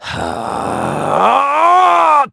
Clause_ice-Vox_Casting3_kr.wav